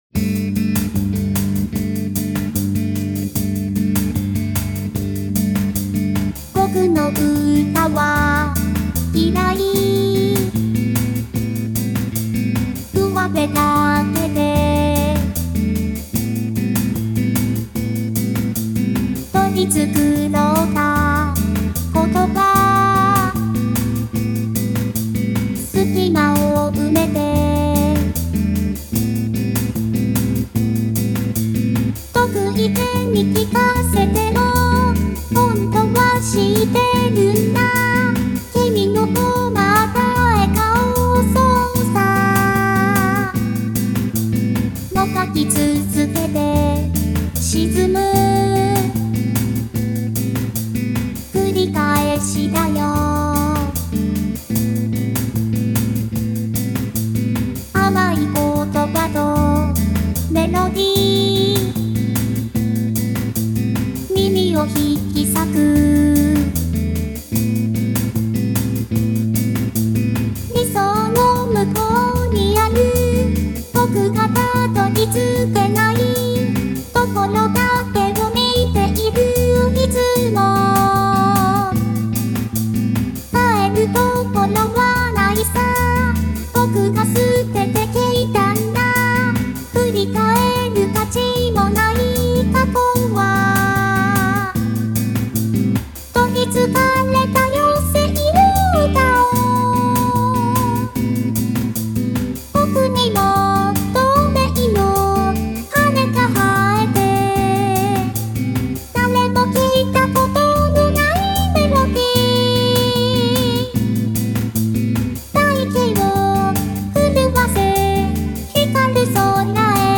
sop-bossa.mp3